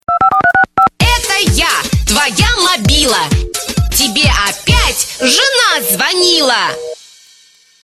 Главная » Рингтоны » Голосовые рингтоны